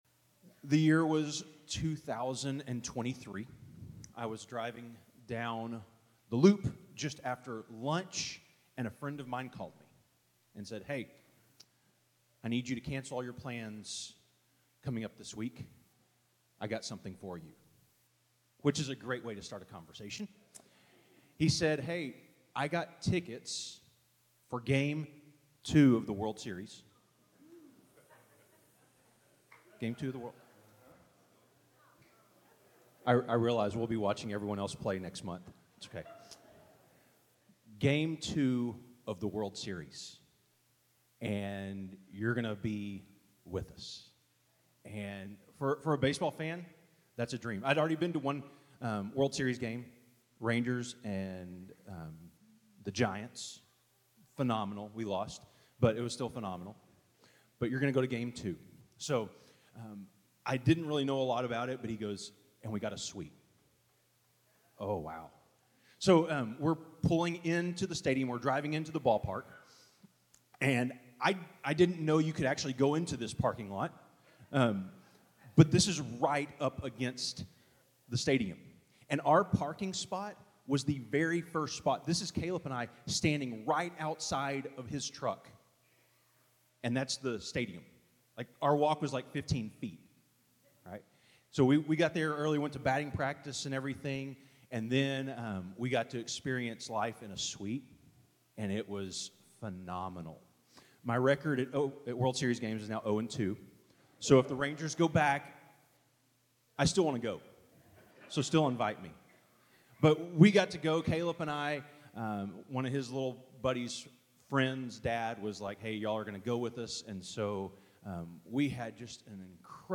In this week’s sermon from Shiloh Road Church of Christ, we explore one of the most personal and powerful moments in Scripture: God’s unexpected invitation to Mary in Luke 1.